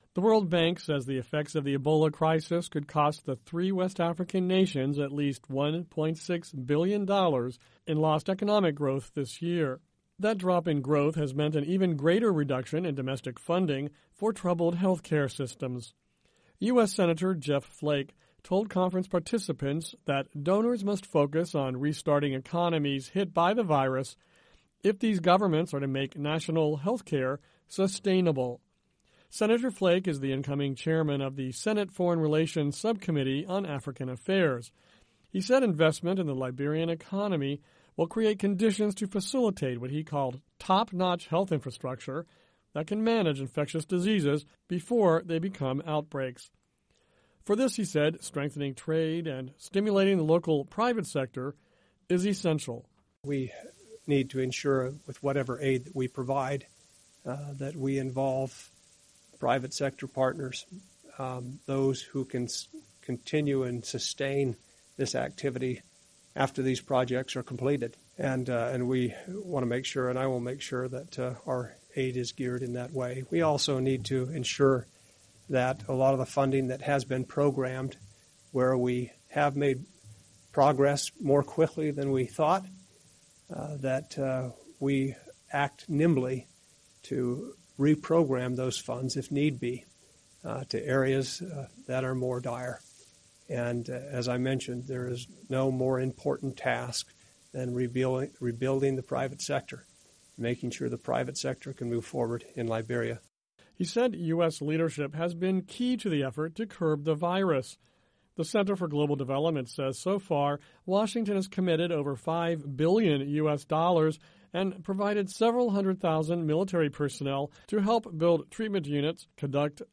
US Senator Jeff Flake told participants at a recent panel discussion at the Washington-based Center for Global Development that donors must focus on restarting economies if these governments are to make national health care sustainable.